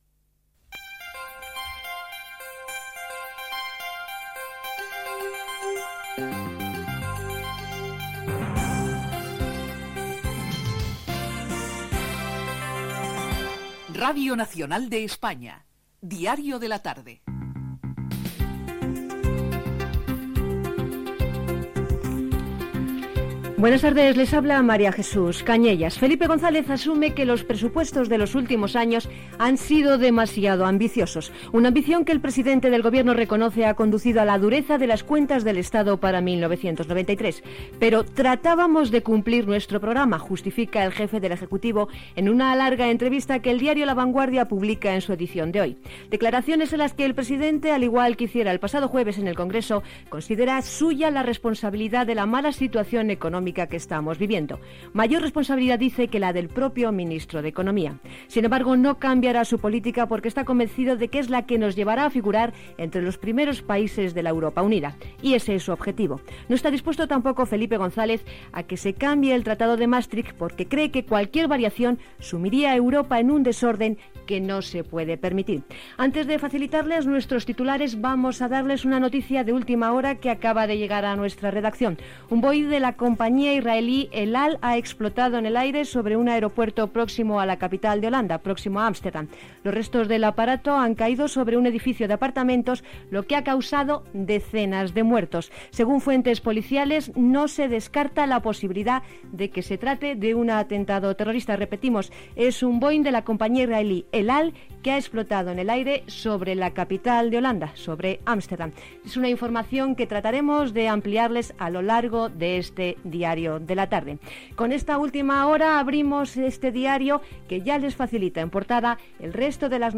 Careta del programa, declaracions del president Felipe González, explosió d'un Boeing israelià a Holanda.
Informatiu